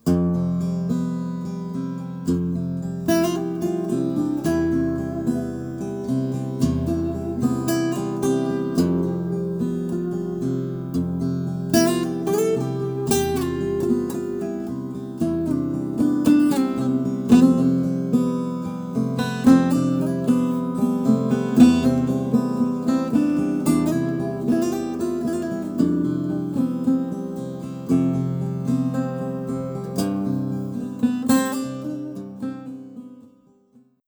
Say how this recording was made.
I think I recorded it on my phone using Garage Band.